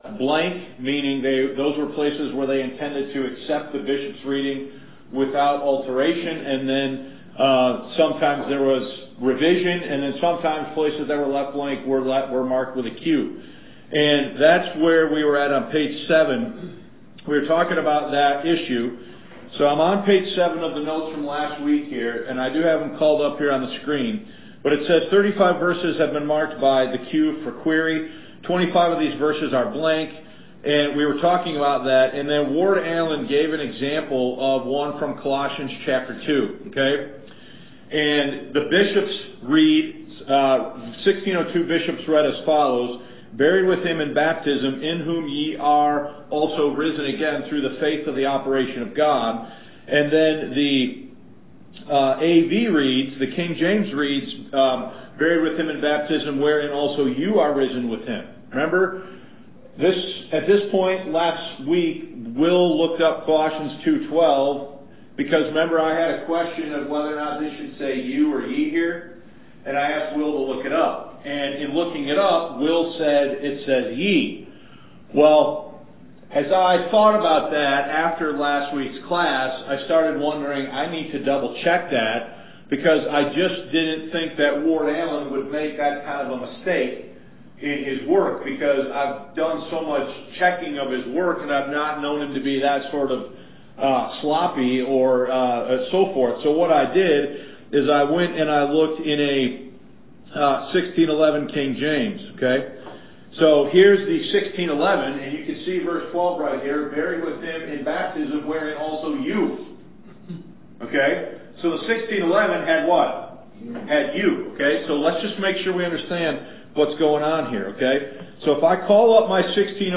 Please note that the same notes were used to teach Lessons 167 and Lesson 168 on Sunday, February 20, 2022.